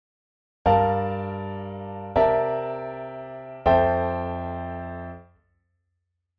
A common one is to play rootless ninth chords for the ii minor seventh and I major seventh chord, and a thirteenth chord for the V dominant seventh chord.
G minor 9 (rootless)
C 13 (rootless)
F major 9 (rootless)
2-5-1-F-rootless-jazz-piano-chords.mp3